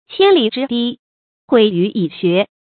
千里之堤，毁于蚁穴 qiān lǐ zhī dī，huǐ yú yǐ xué 成语解释 一个小小的蚂蚁洞，可以使千里长堤溃决。